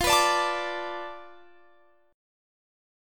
Listen to F6b5 strummed